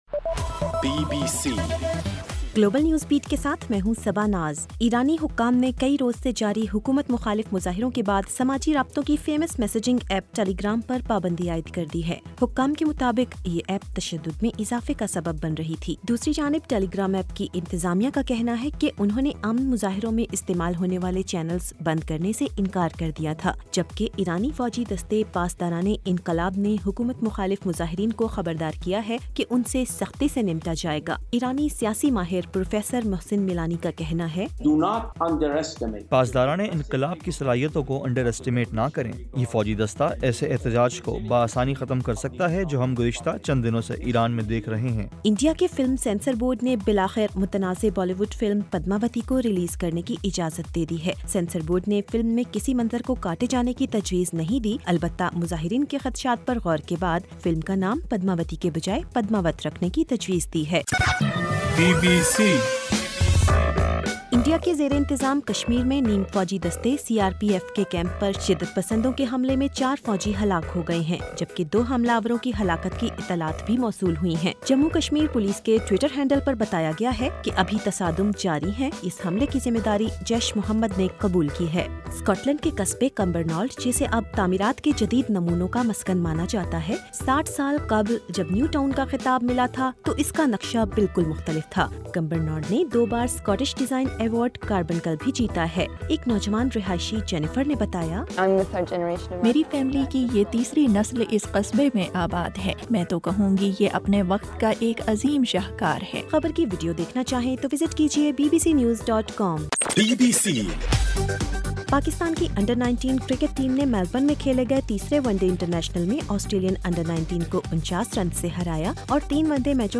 گلوبل نیوز بیٹ بُلیٹن اُردو زبان میں رات 8 بجے سے صبح 1 بجے ہرگھنٹےکے بعد اپنا اور آواز ایف ایم ریڈیو سٹیشن کے علاوہ ٹوئٹر، فیس بُک اور آڈیو بوم پر سنئیِے